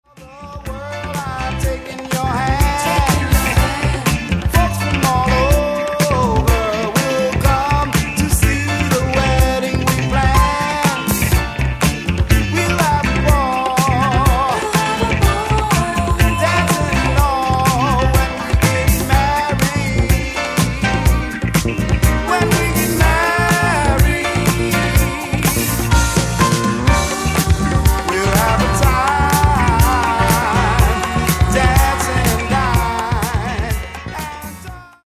Genre:   Latin Disco Soul